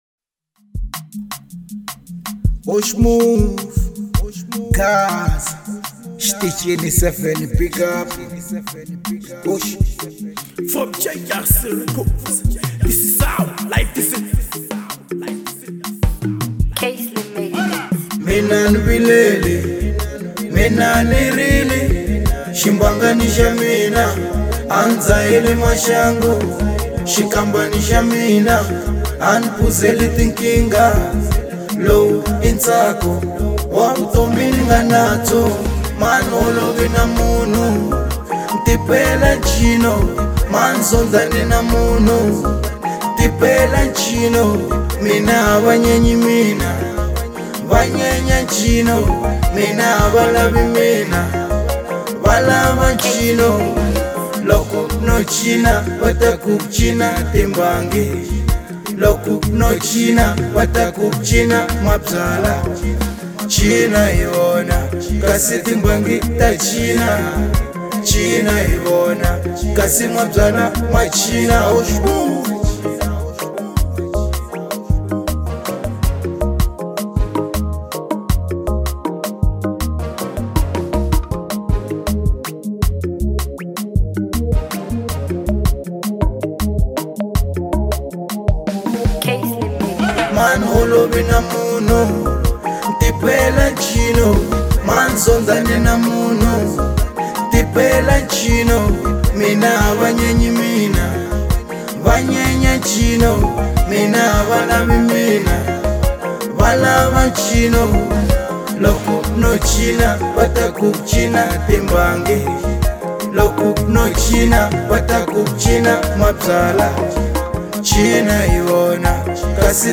03:23 Genre : African Rumba Size